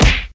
player_nunchuck_hit.ogg